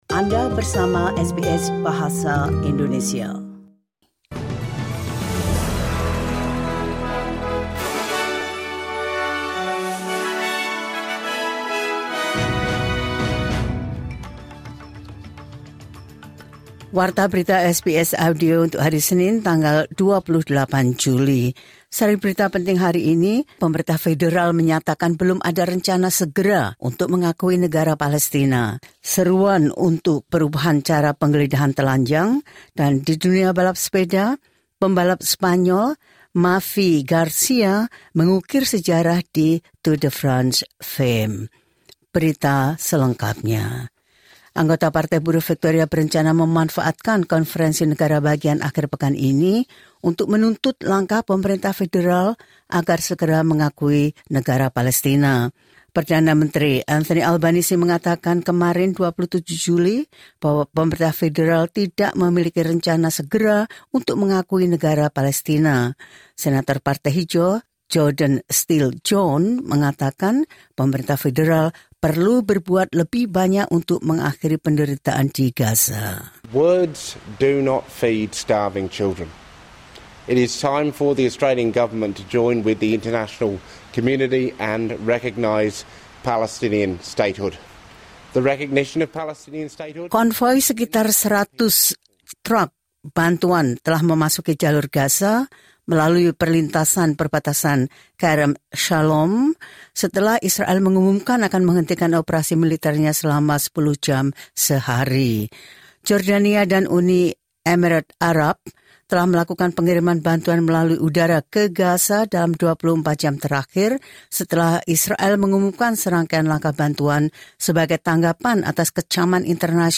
The latest news SBS Audio Indonesian Program – 28 Jul 2025.